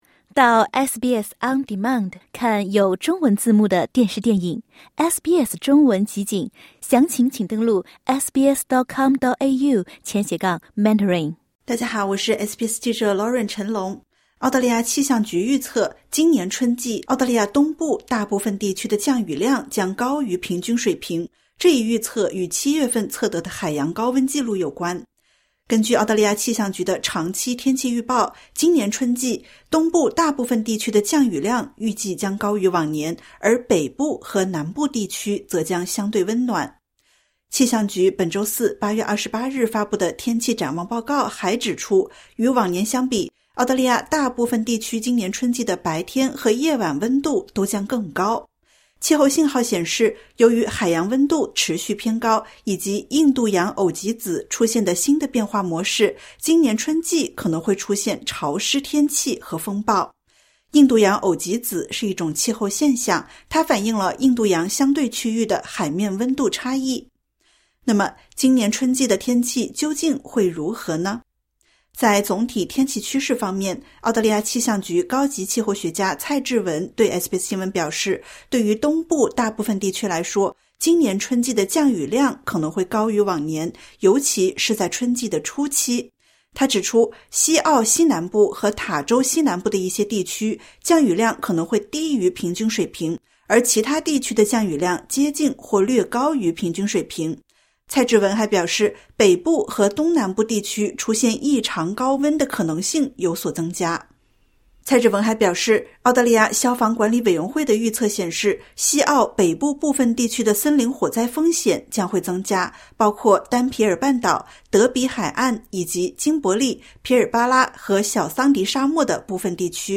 澳大利亚气象局预测，今年春季，澳大利亚东部大部分地区的降雨量将高于平均水平，这一预测与7月份测得的海洋高温纪录有关。点击 ▶ 收听完整报道。